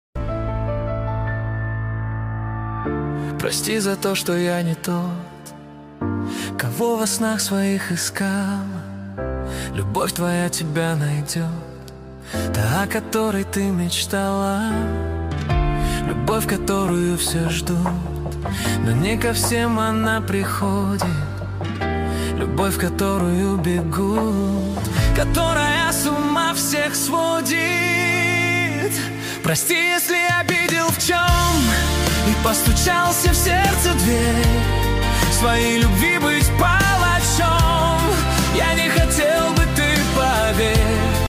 15 декабрь 2025 Русская AI музыка 123 прослушиваний